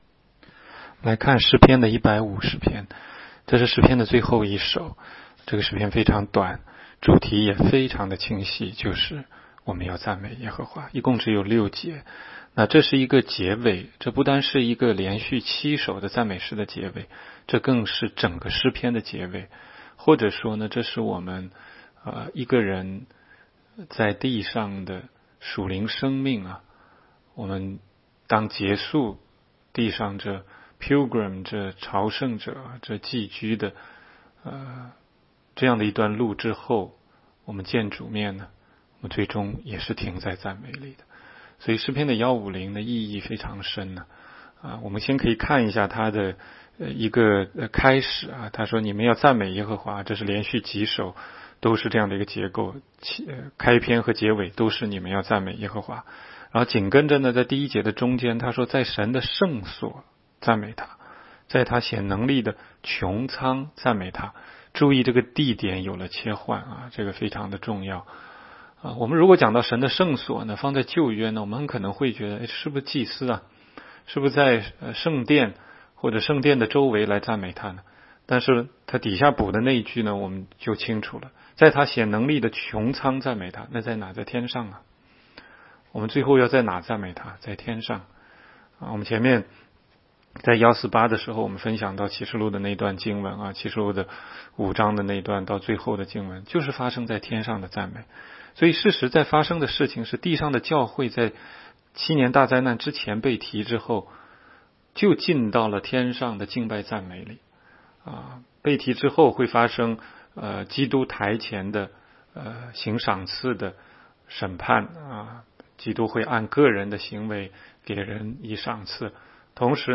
16街讲道录音 - 每日读经 -《 诗篇》150章